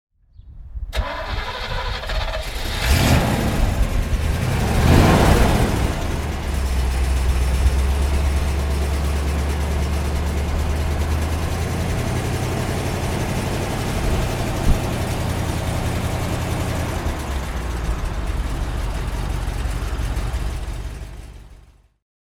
Motorsounds und Tonaufnahmen zu ISO Fahrzeugen (zufällige Auswahl)